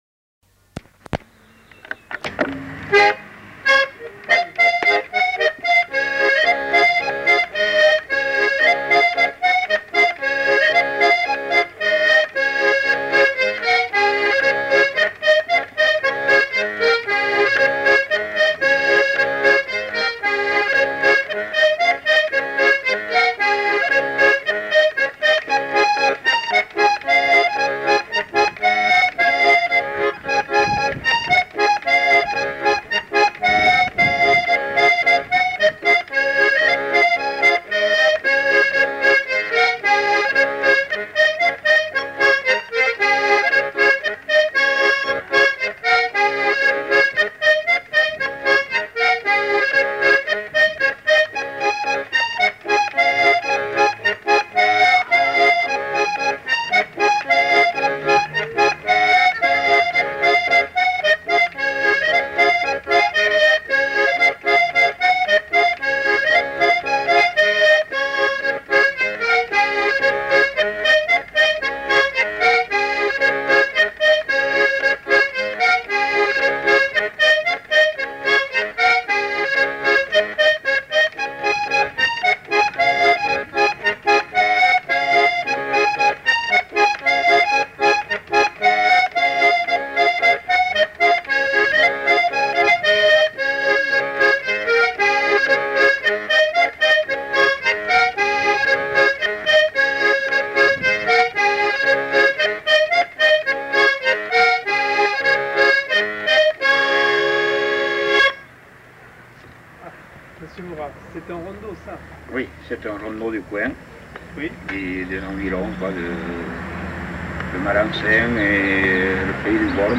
Lieu : Mimizan
Genre : morceau instrumental
Instrument de musique : accordéon diatonique
Danse : rondeau
Notes consultables : Discussion sur le titre à la fin de l'item.